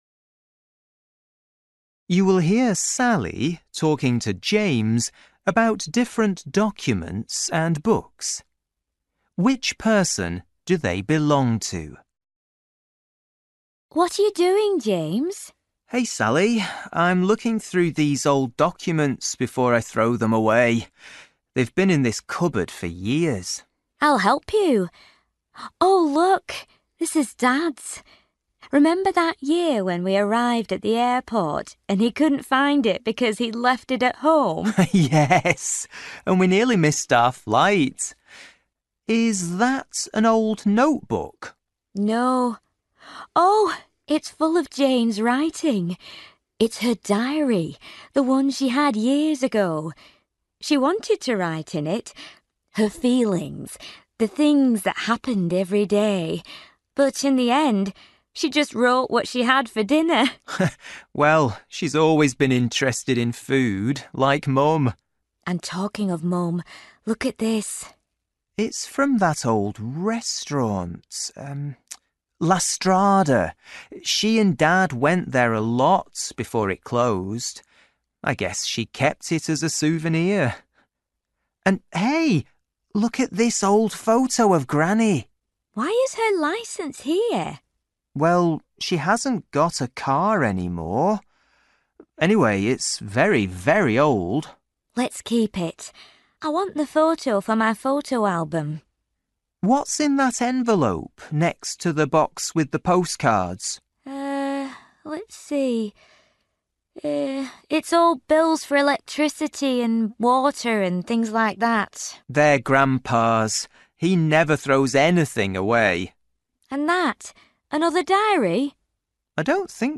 You will hear Sally talking to James about different documents and books.